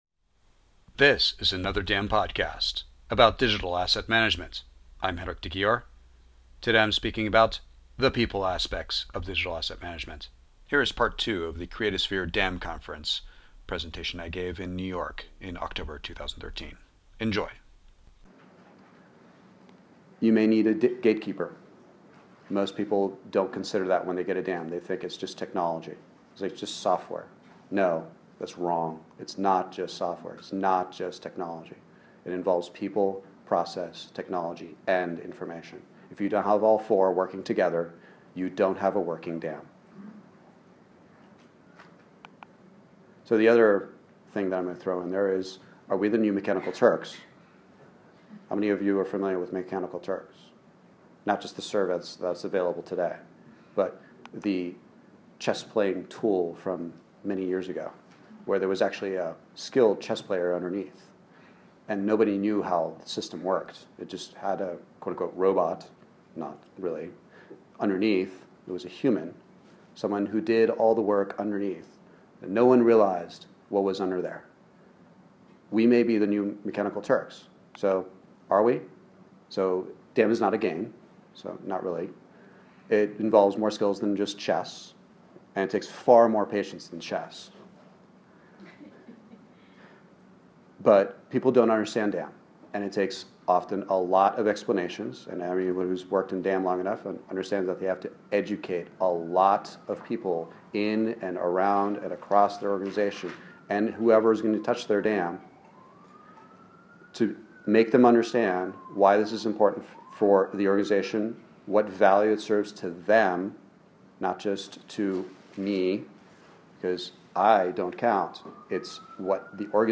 This presentation was audio recorded so it could be shared with you.